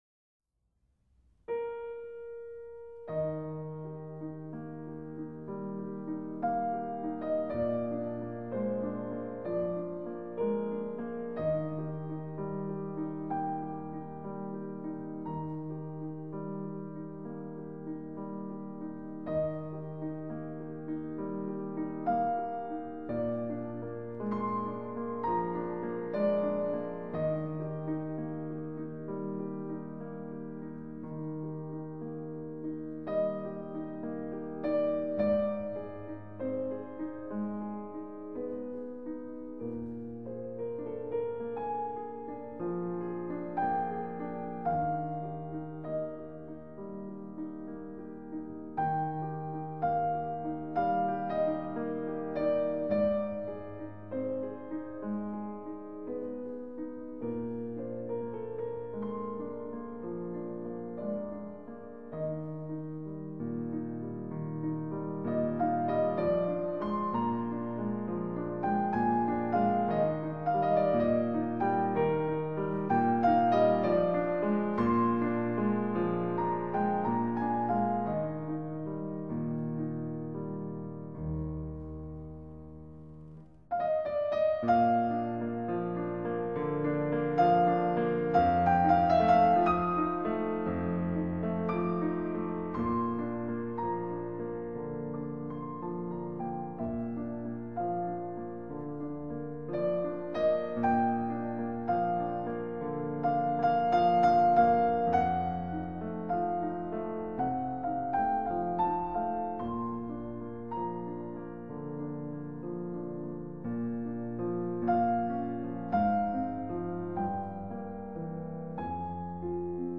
旋律優美、詮釋到位、錄音超絕的版本！
運指細膩，琴音珠圓玉潤，值得細細品味。
空間感十足，琴音柔美動聽！
這張專輯的錄音地點在倫敦的聖猶達教堂， 現場空間感十足，鋼琴的尾韻豐富而細緻，盡顯夜曲的柔美質感。